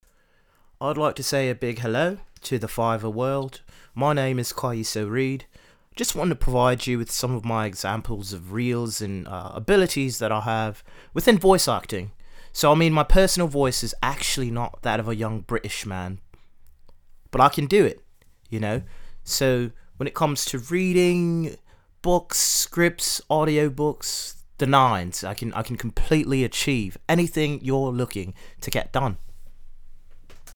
English gentlemen Reel
Young Adult
BRITISH REEL.mp3